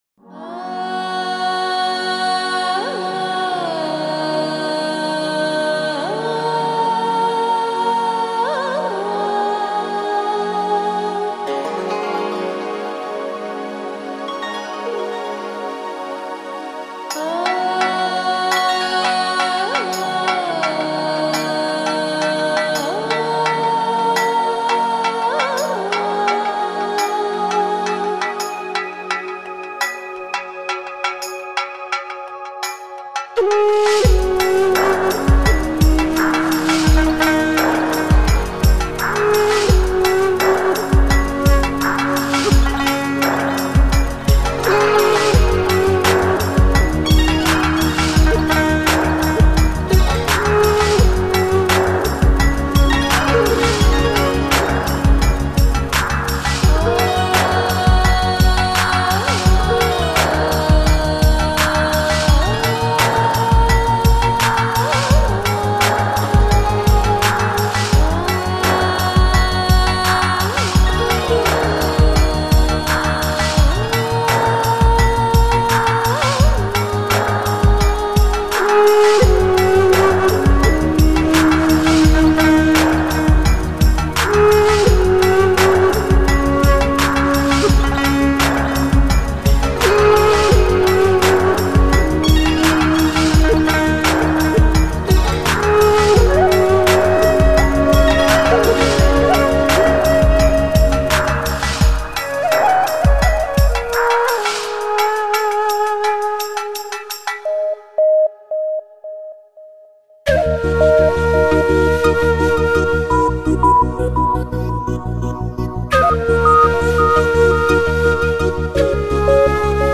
New Age, Meditative Quality